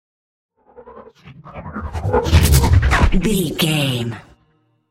Sci fi hit technology electricity
Sound Effects
heavy
intense
dark
aggressive
hits